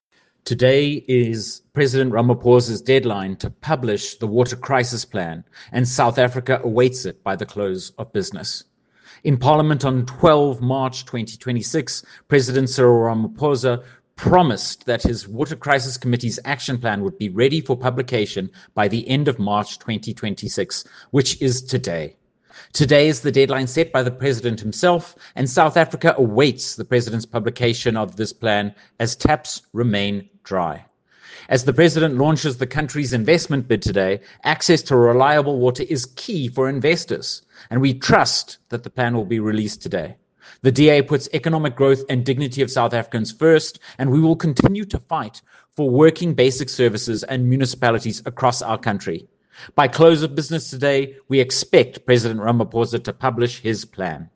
Soundbite by Stephen Moore MP.